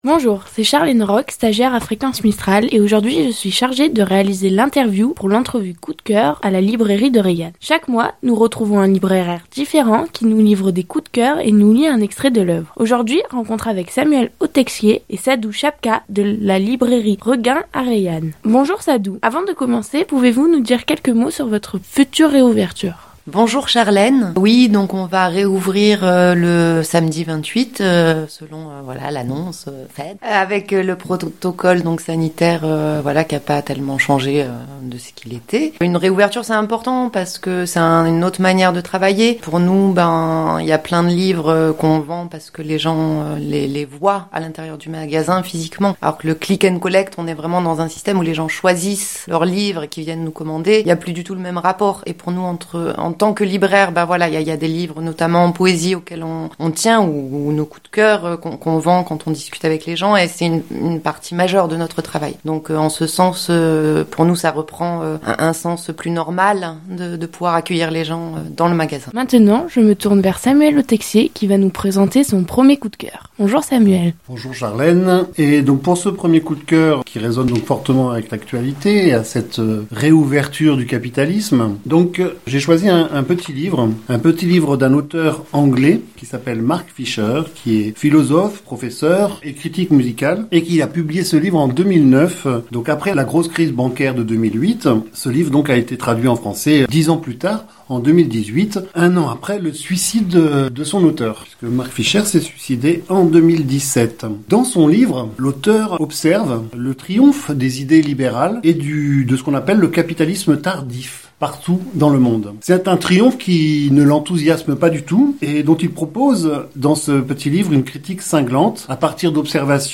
Dans notre rendez-vous mensuel avec les libraires de la région, nous vous proposons d'écouter une entrevue avec nos deux amis de la librairie Regain située à Reillanne.